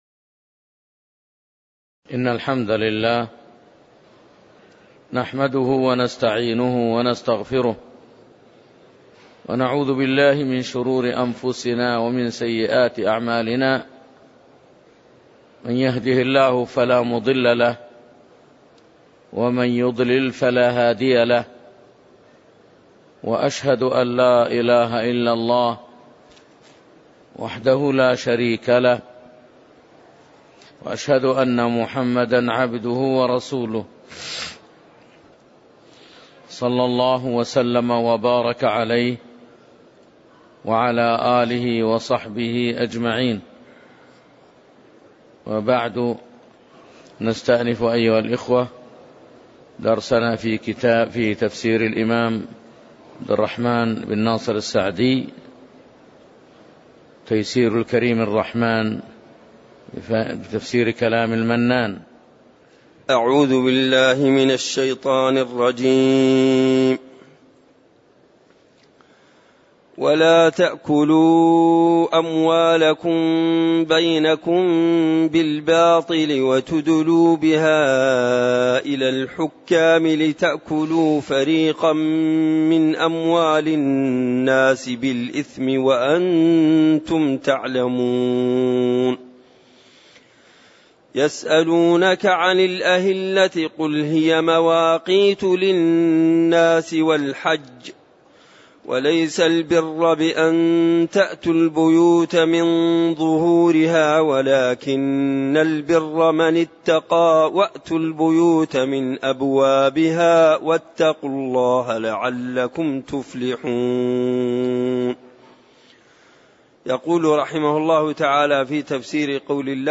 تاريخ النشر ٥ ذو القعدة ١٤٣٨ هـ المكان: المسجد النبوي الشيخ